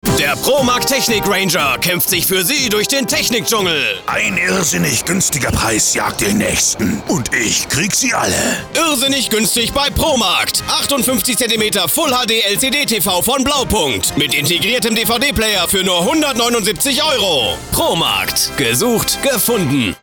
Entwürfe für ProMarkt , Beileger EM 2012 [slideshow id=12] Funkspot Ranger Funkspot Technik-Ranger Beileger 0% Finanzierung Posted 8.